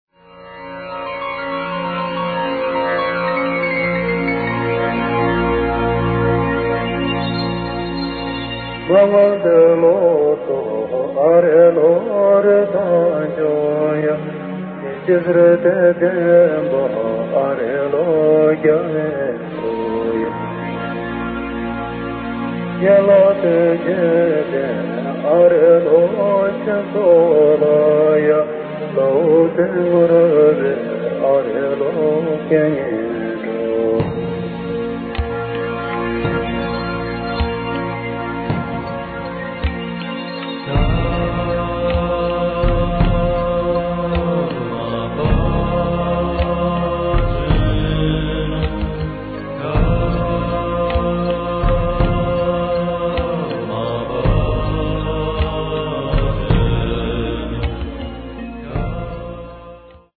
Mal zart, mal kraftvoll und stark musikalisch orientiert